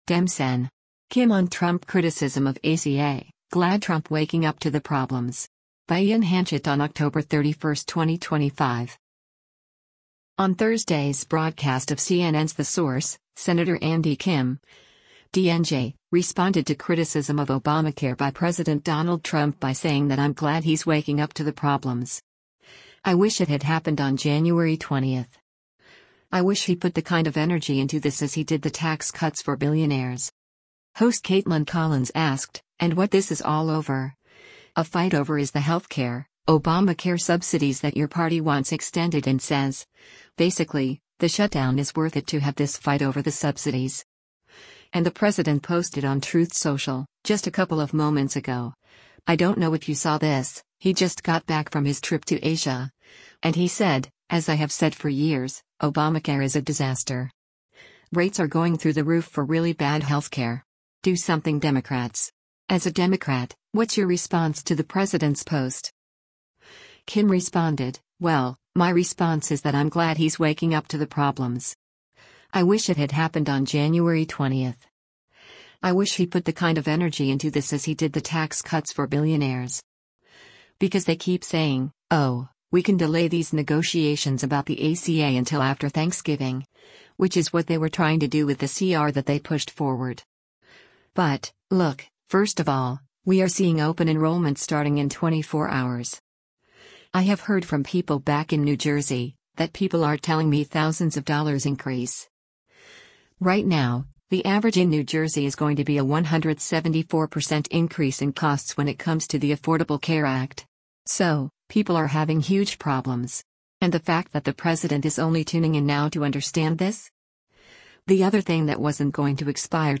On Thursday’s broadcast of CNN’s “The Source,” Sen. Andy Kim (D-NJ) responded to criticism of Obamacare by President Donald Trump by saying that “I’m glad he’s waking up to the problems. I wish it had happened on January 20. I wish he put the kind of energy into this as he did the tax cuts for billionaires.”